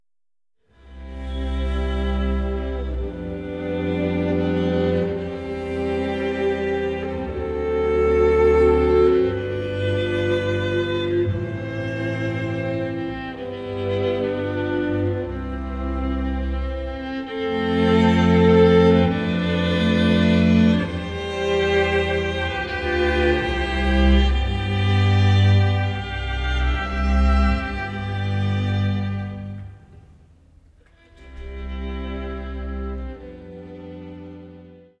Vokalensemble